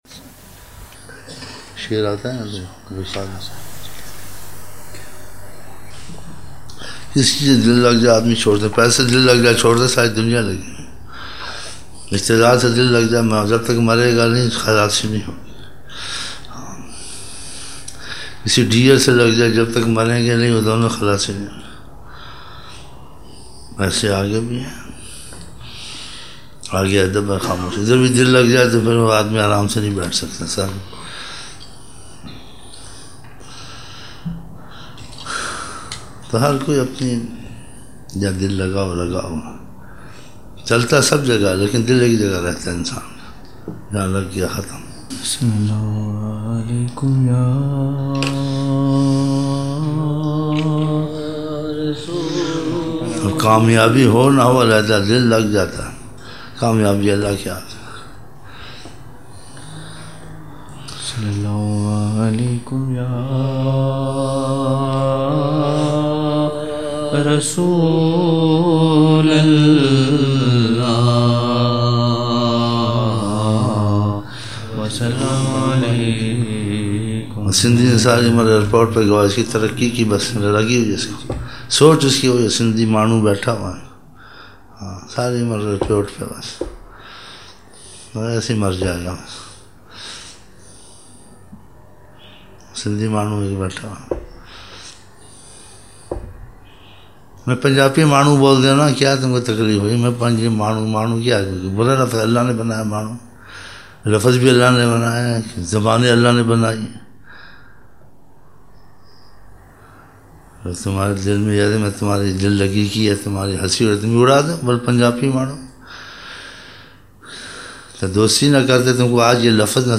24 November 1999 - Zohar mehfil (16 Shabaan)